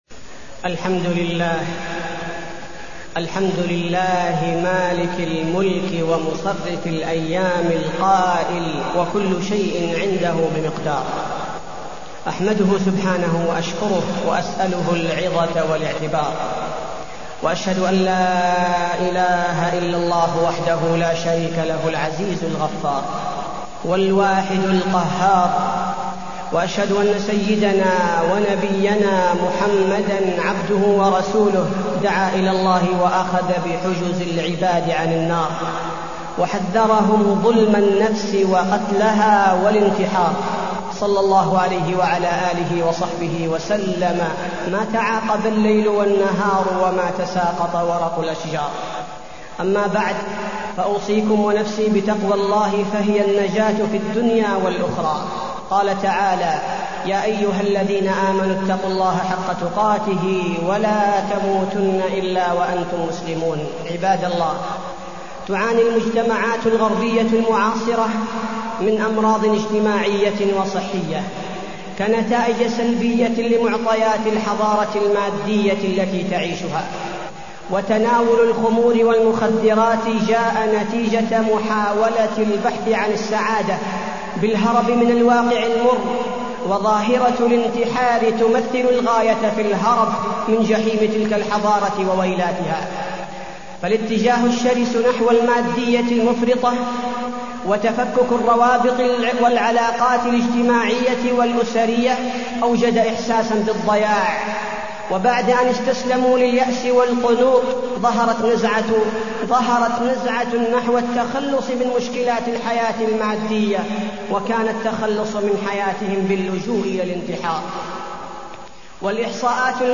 تاريخ النشر ١٥ ذو القعدة ١٤٢١ هـ المكان: المسجد النبوي الشيخ: فضيلة الشيخ عبدالباري الثبيتي فضيلة الشيخ عبدالباري الثبيتي الانتحار